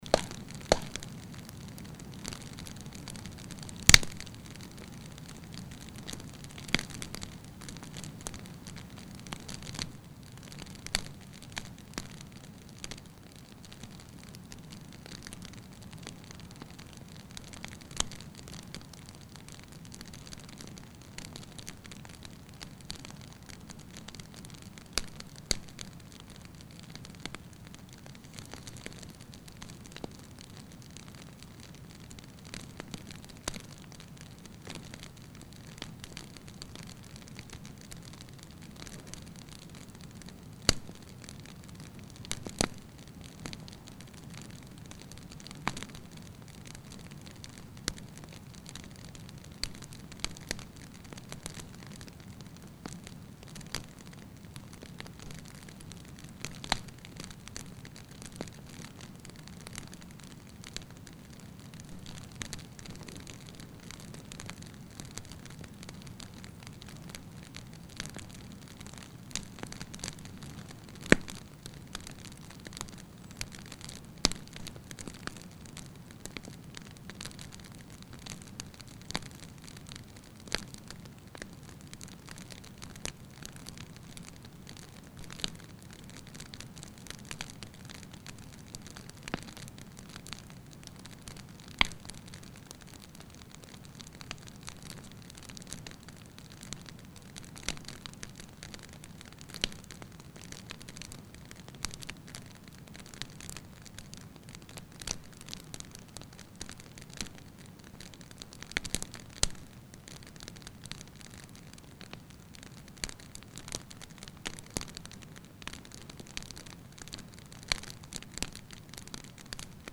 3D spatial surround sound "Fire, bonfire"
3D Spatial Sounds